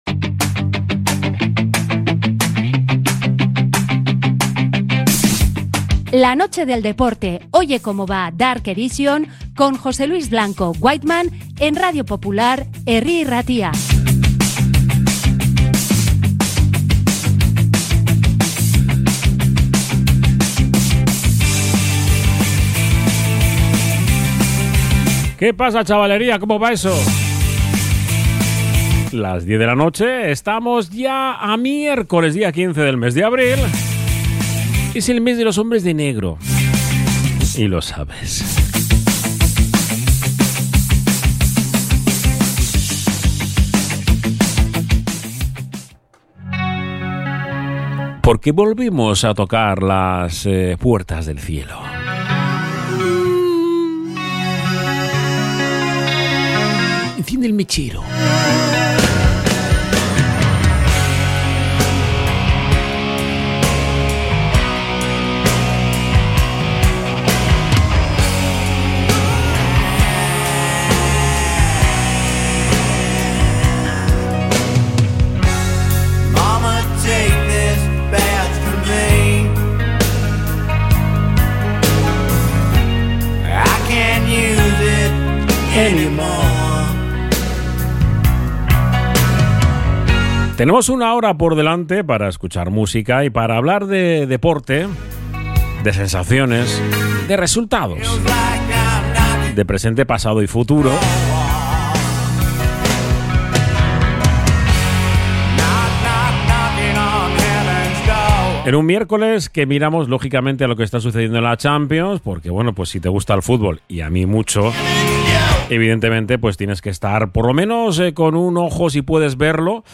Oye Cómo Va Dark Edition 15.04.2026 Entrevista a Elgezabal, entradas Athletic, viaje a Salónica...